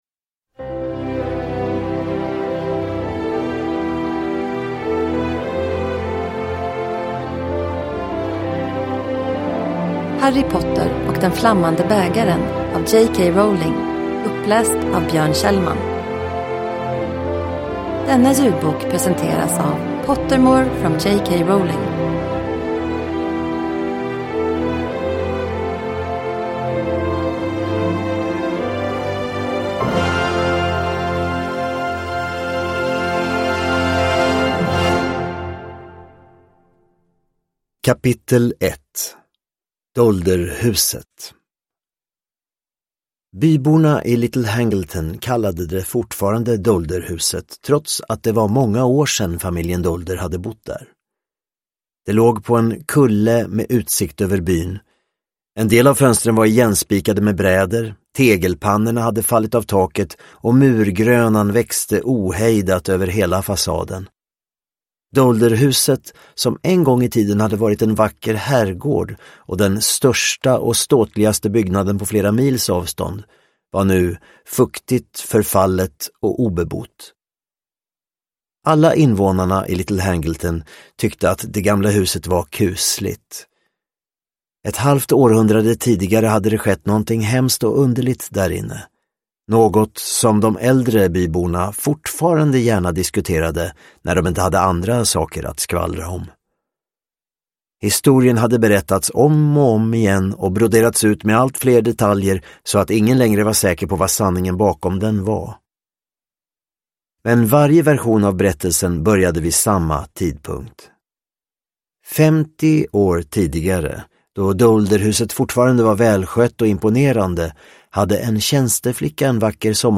Harry Potter och Den Flammande Bägaren (ljudbok) av J.K. Rowling